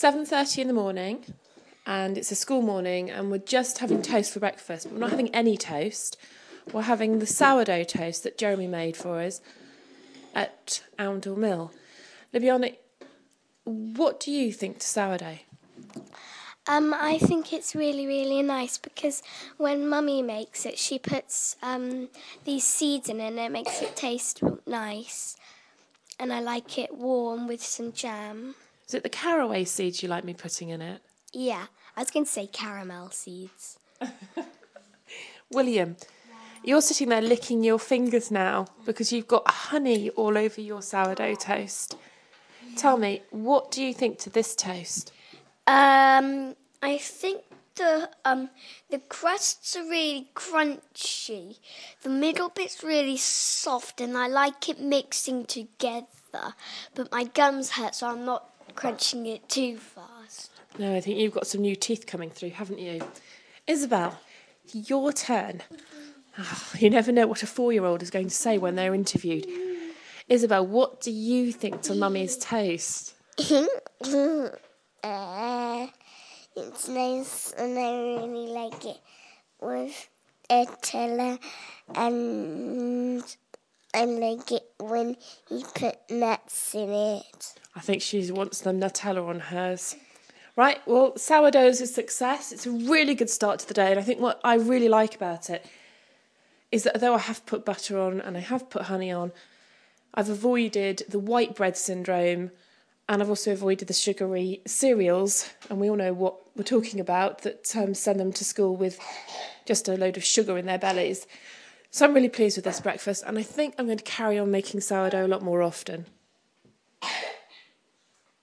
Children eating sourdough toast